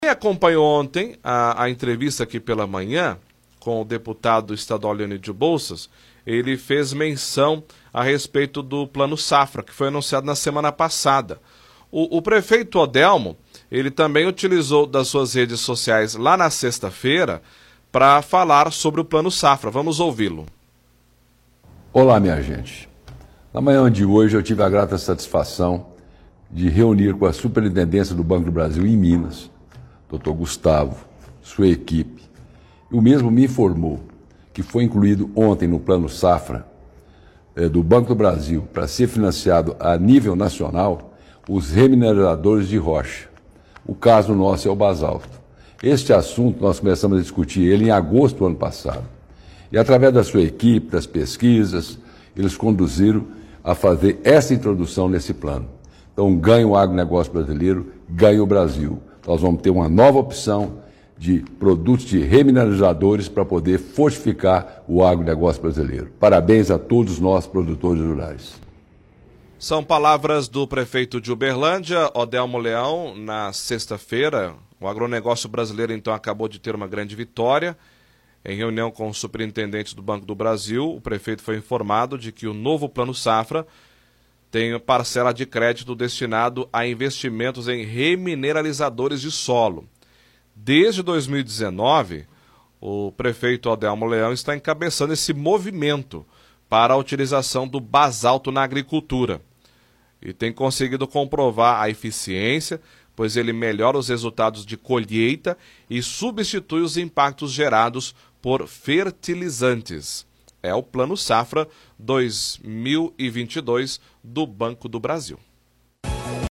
Pronunciamento do prefeito Odelmo sobre o Plano Safra
Exibe áudio de pronunciamento que o prefeito fez na sexta-feira em suas redes sociais.
Pronunciamento-do-prefeito-Odelmo-sobre-o-Plano-Safra.mp3